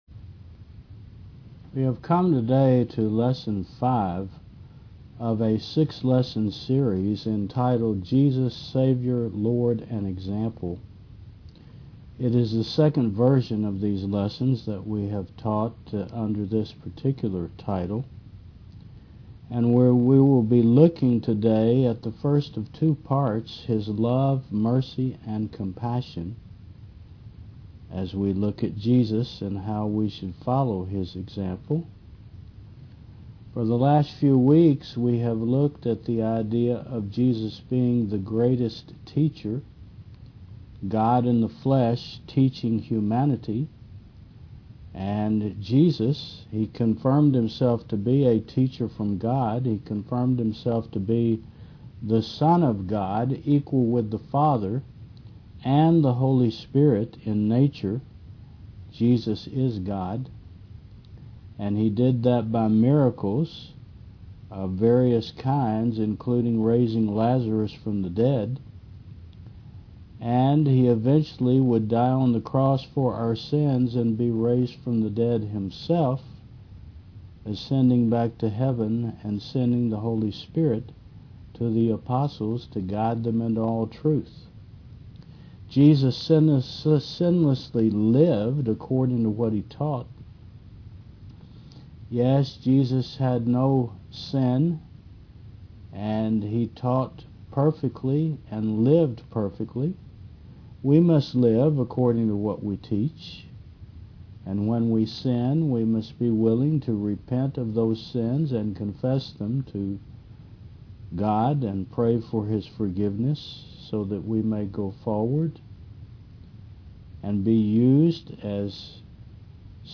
Jesus Our Savior Lord And Example v2 Service Type: Mon. 9 AM What does it mean to love?